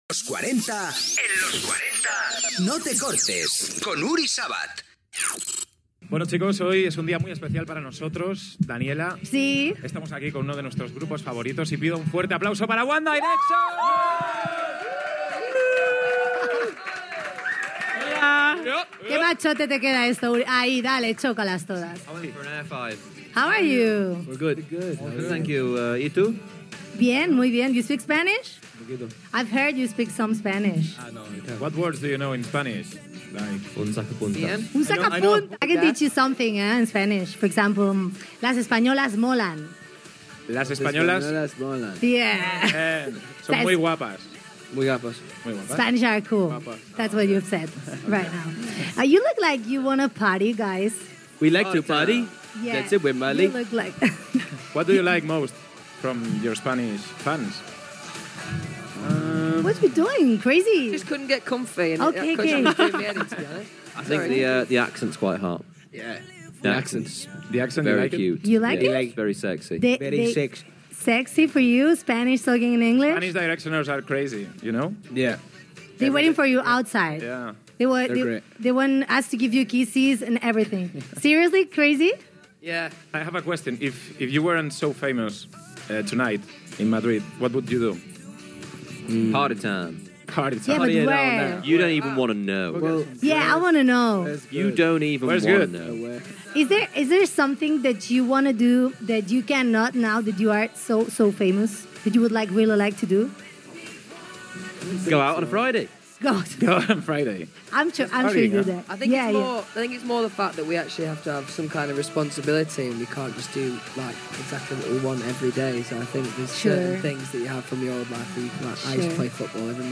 Indicatiu del programa, entrevista als integrants del grup musical One Direction
Entreteniment
FM